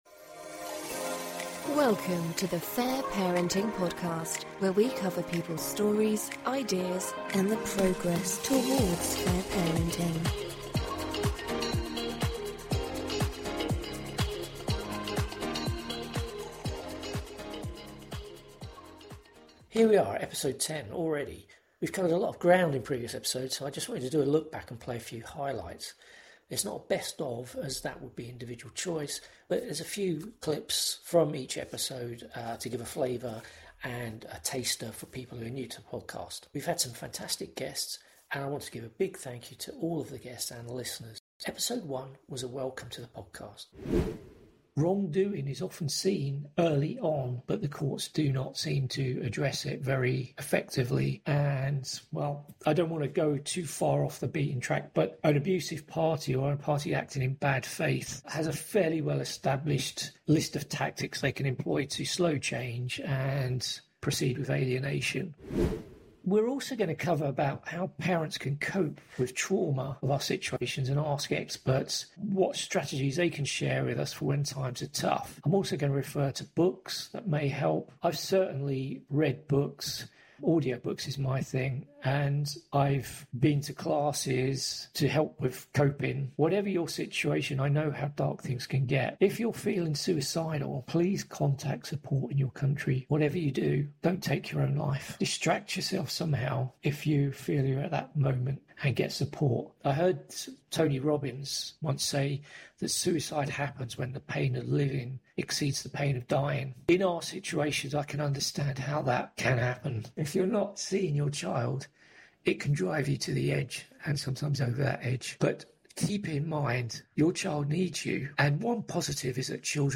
We have covered a lot of ground so I wanted to do a look back at play a few highlights from the shows.